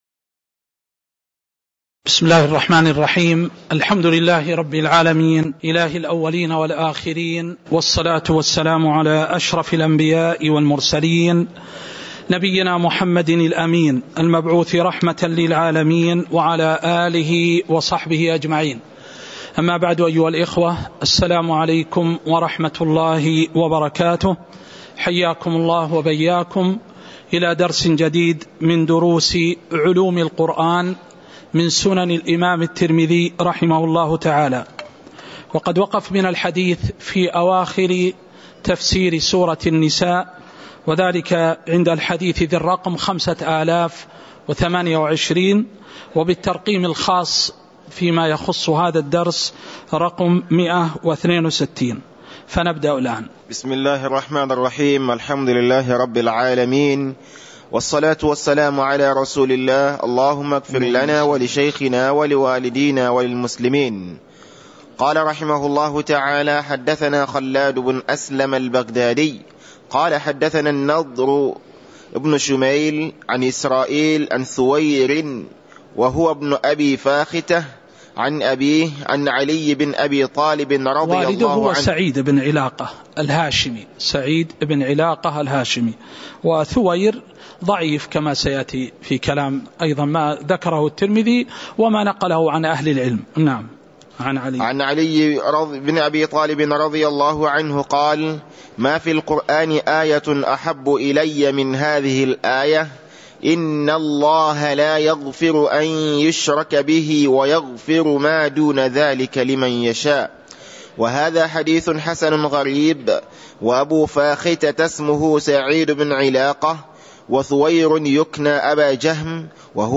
تاريخ النشر ٢٤ ربيع الأول ١٤٤٣ هـ المكان: المسجد النبوي الشيخ